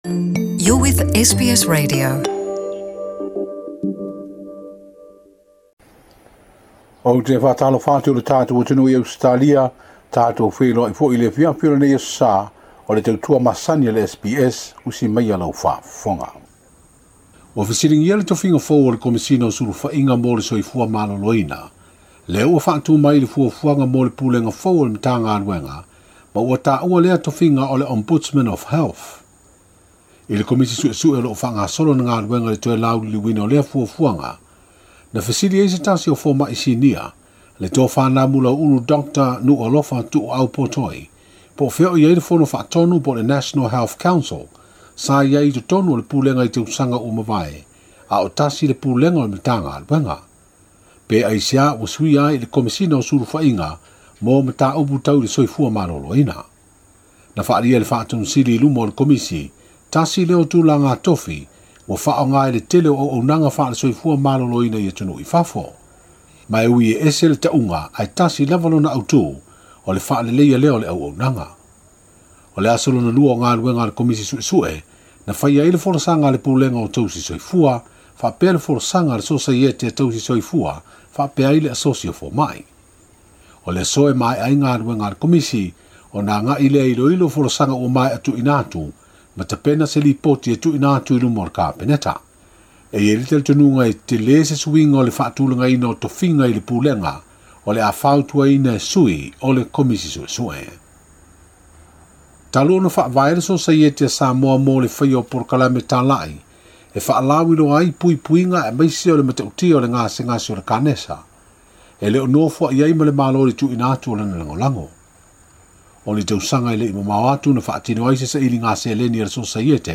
Talafou o Samoa mo le Aso 20 o Iuni.